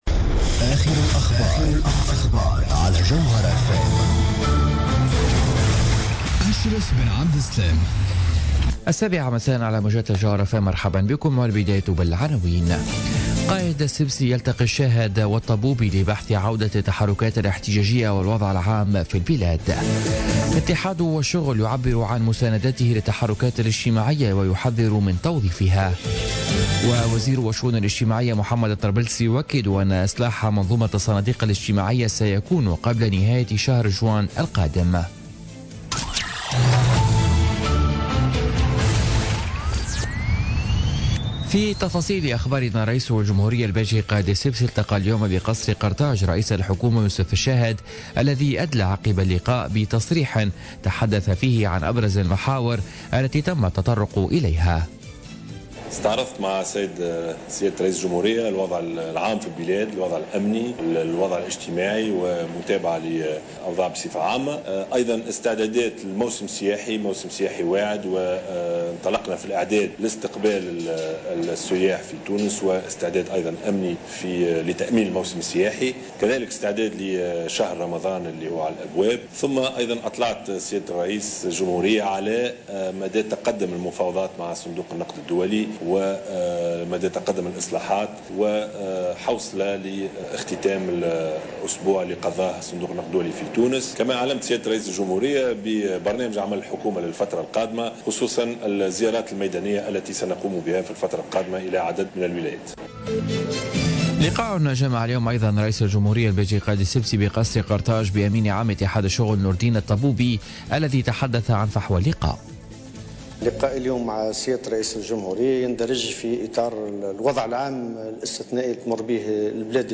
نشرة أخبار السابعة مساء ليوم الاثنين 17 أفريل 2017